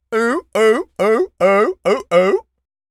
pgs/Assets/Audio/Animal_Impersonations/seal_walrus_bark_high_02.wav at master
seal_walrus_bark_high_02.wav